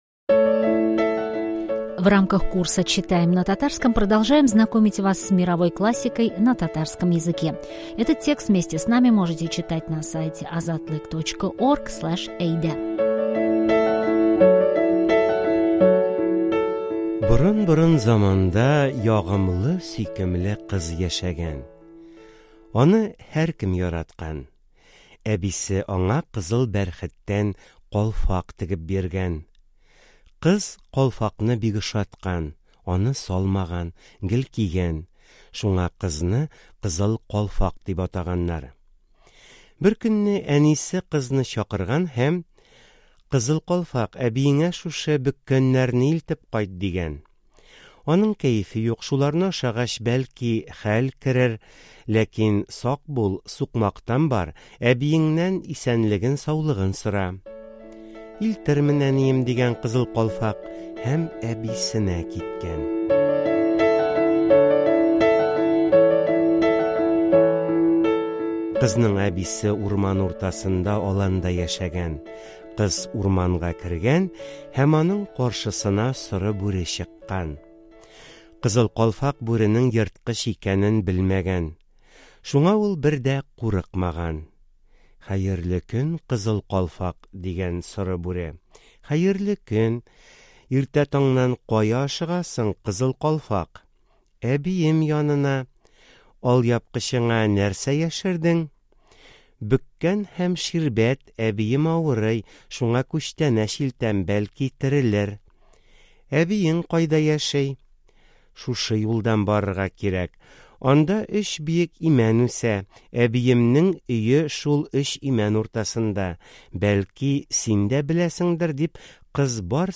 Кызыл калфак (Красная шапочка) – аудиокнига на татарском